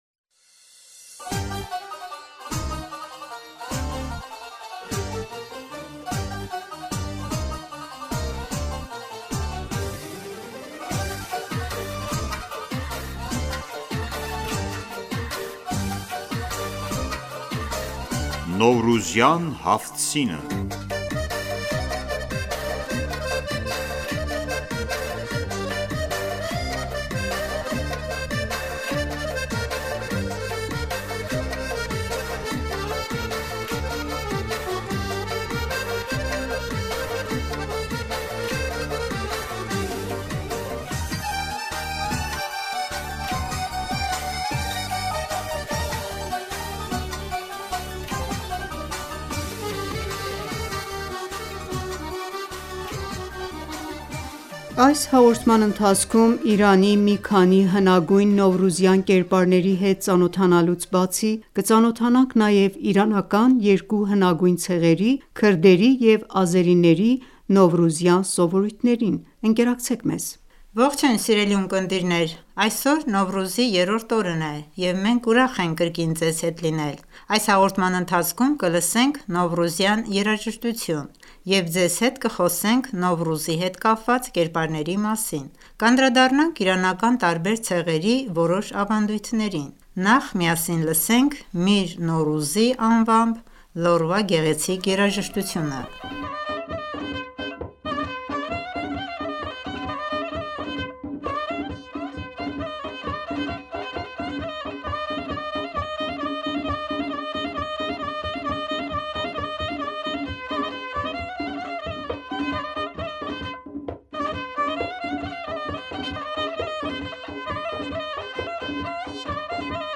Այս հաղորդման ընթացքում կլսենք Նովռուզյան երաժշտություն և ձեզ հետ կխոսենք Նովռուզի հետ կապված կերպարների մասին։